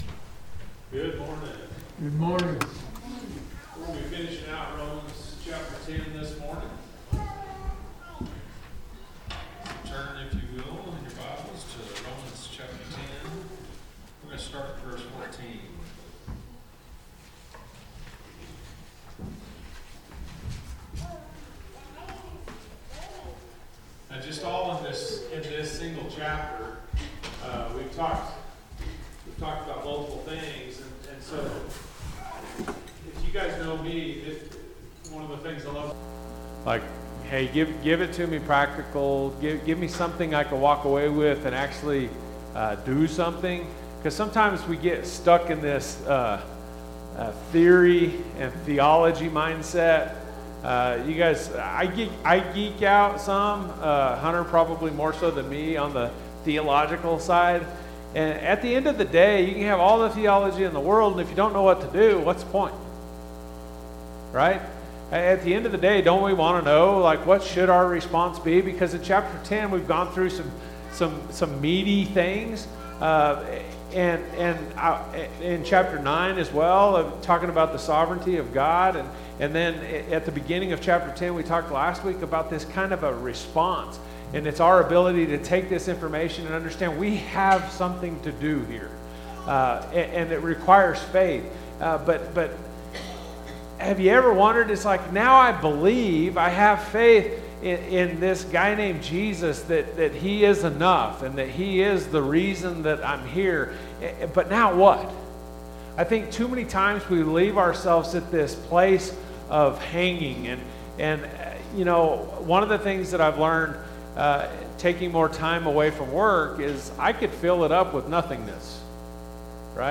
Sunday AM sermon